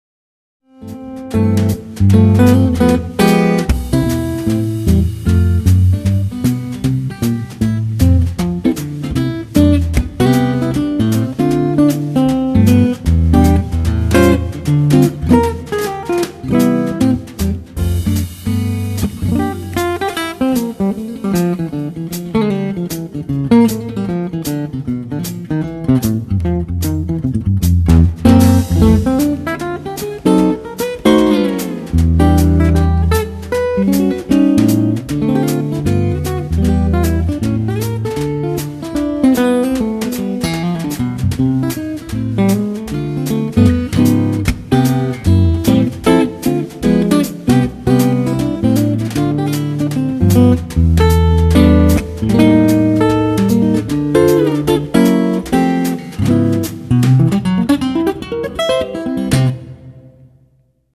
Fingerstyle guiitar